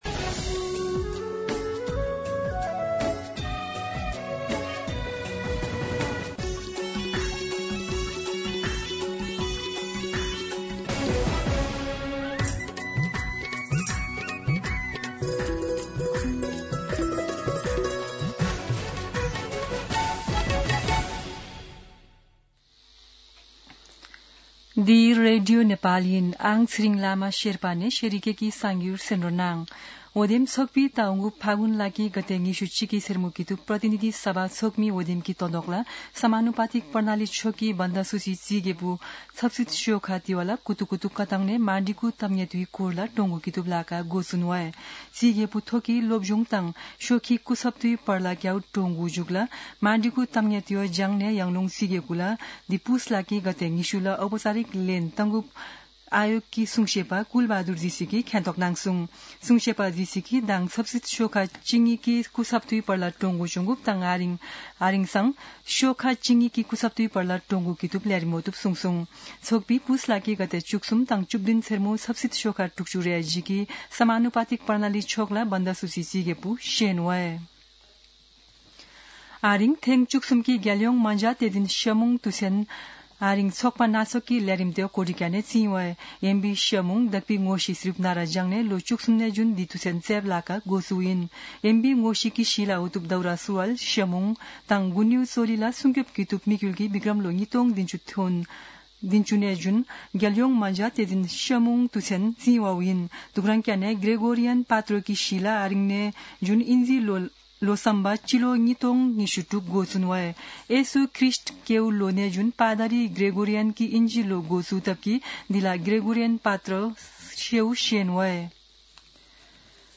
शेर्पा भाषाको समाचार : १७ पुष , २०८२
Sherpa-News-9-17.mp3